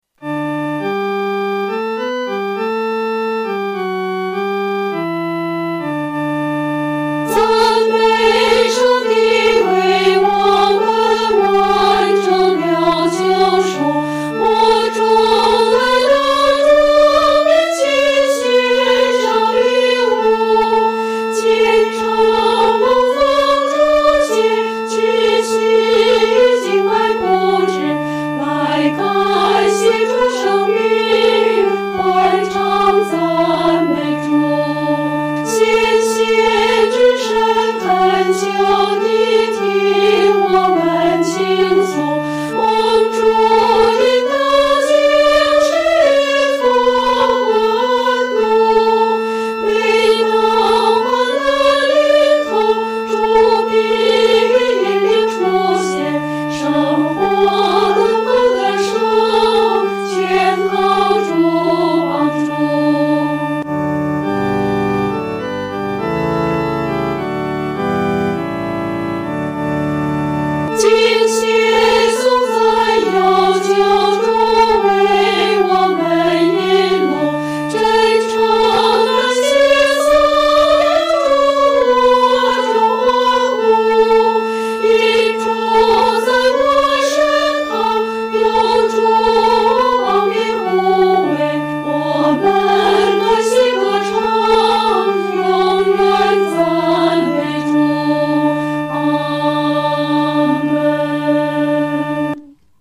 女高
本首圣诗由网上圣诗班（环球）录制
这首曲调格式简单，节奏鲜明，感情丰富，旋律多变，如同海浪起伏。